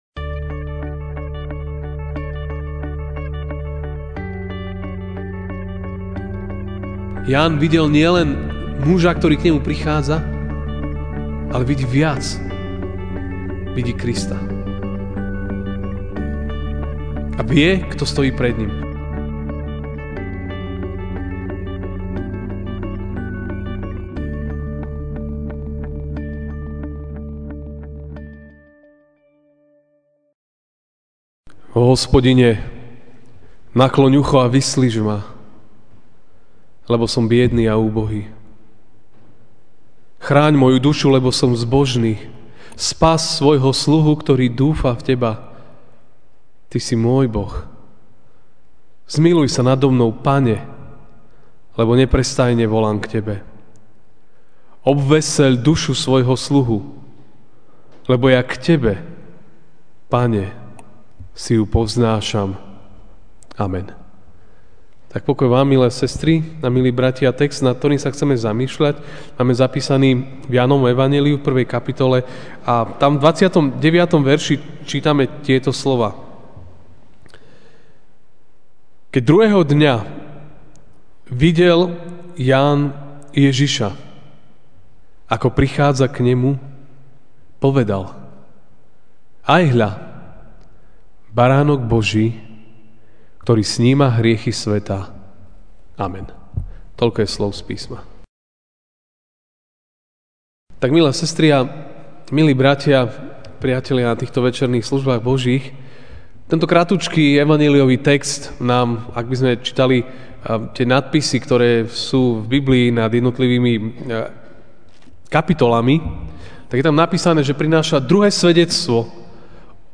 Večerná kázeň